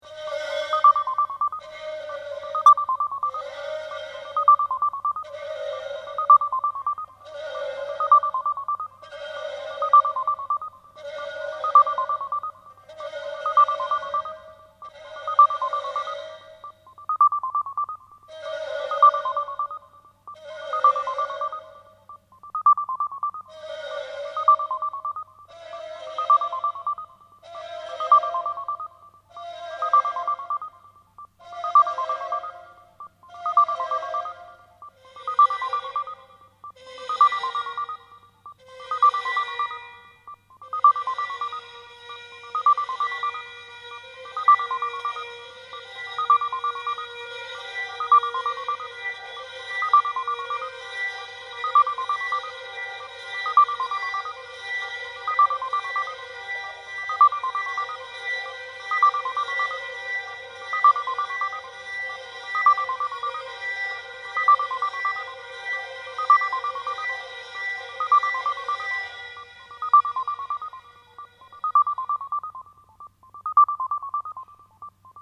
Click above for exerpts of electronic music composed by Lars Akerlund (seweden) and performed live on traditional Thai instrument and computer